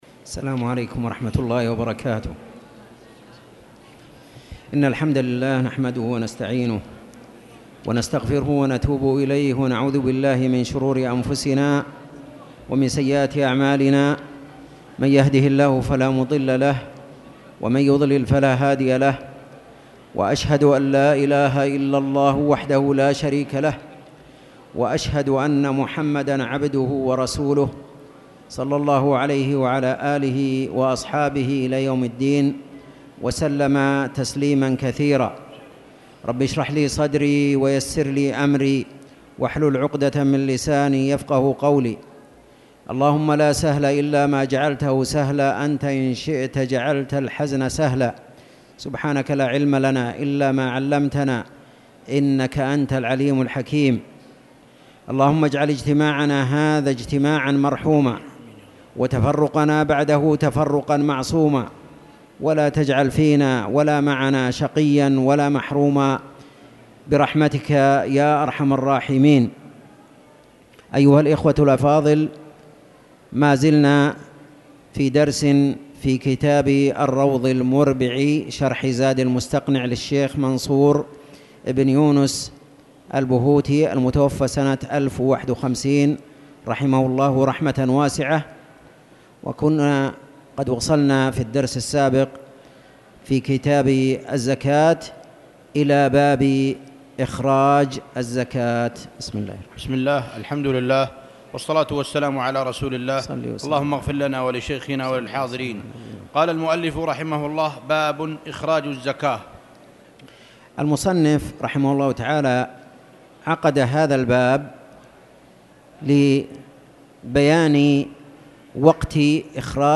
تاريخ النشر ٣٠ محرم ١٤٣٨ هـ المكان: المسجد الحرام الشيخ